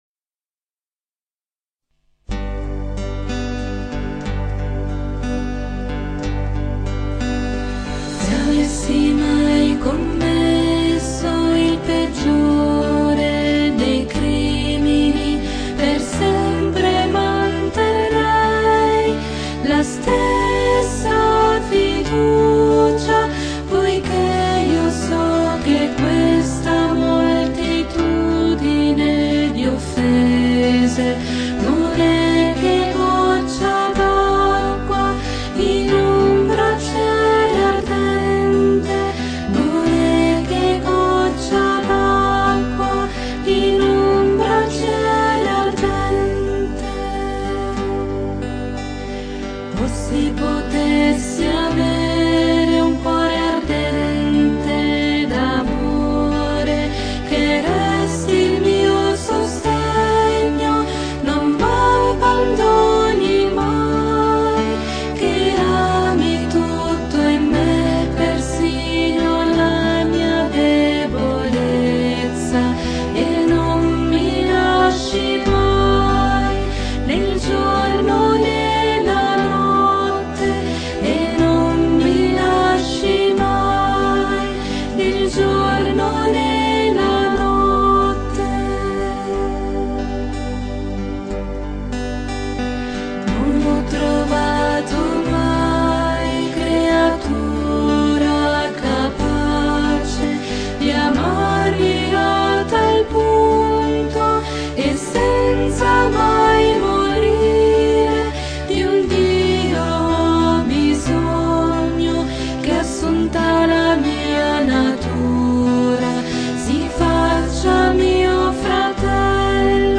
BRANI PER IL CORO LITURGICO DA STUDIARE